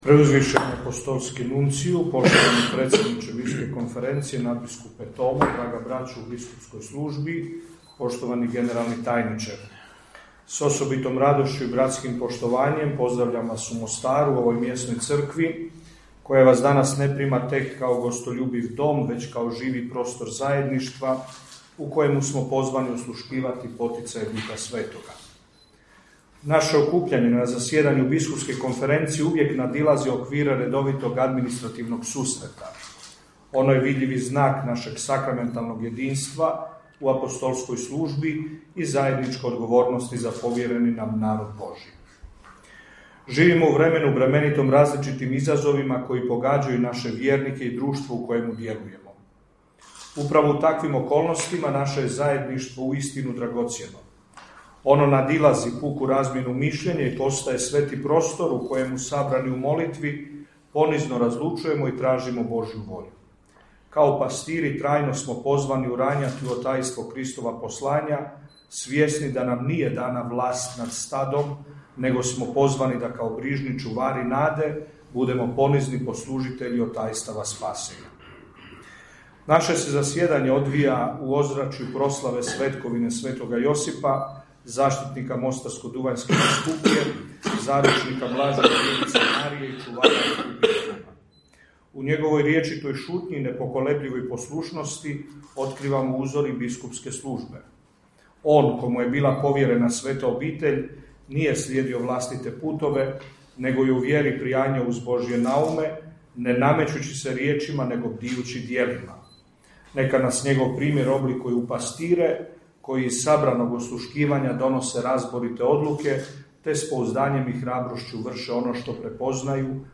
Pozdrave sudionicima 95. zajedanja BK BiH uputio je i domaćin biskup Petar Palić.